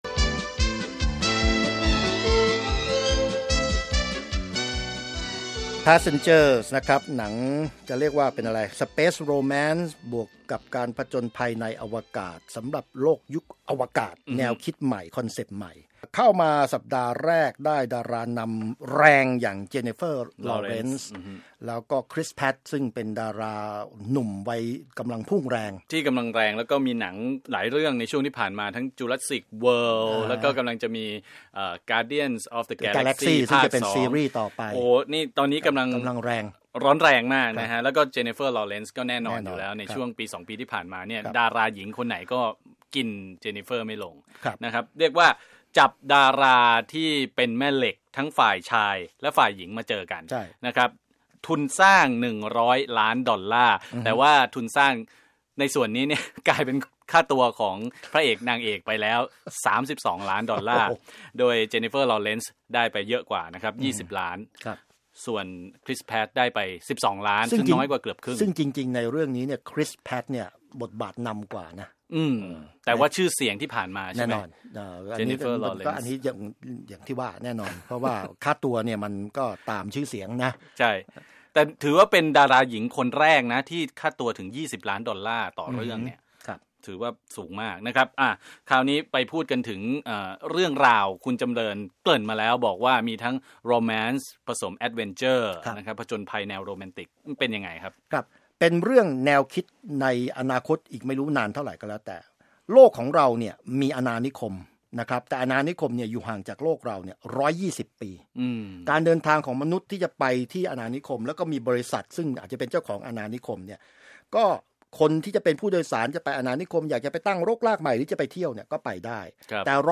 คุยหนัง Passengers